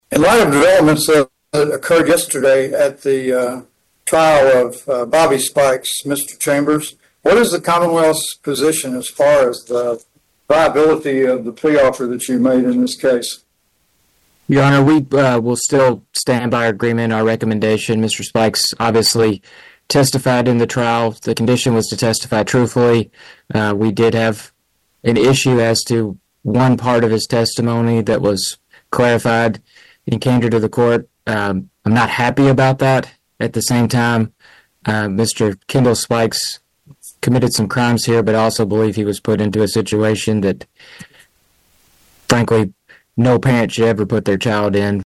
Judge John Atkins and special prosecutor Blake Chambers discussed the setting.